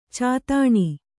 ♪ cātāṇi